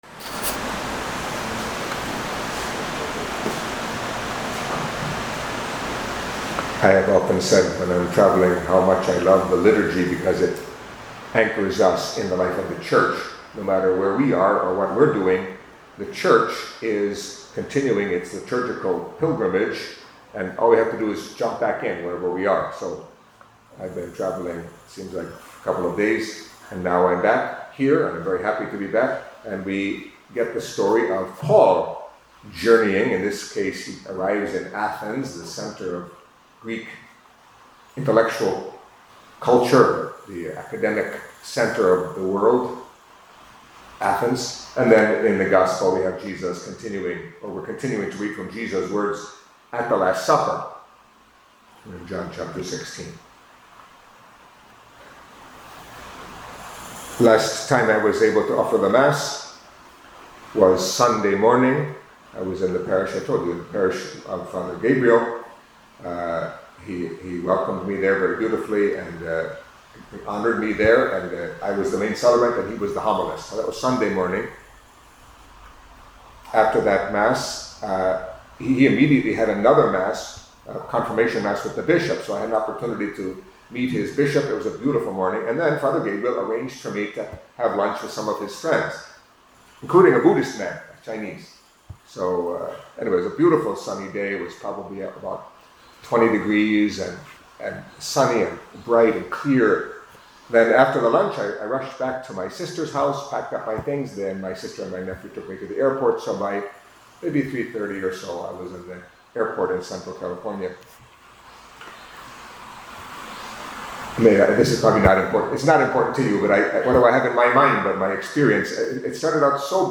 Catholic Mass homily for Wednesday of the Sixth Week of Easter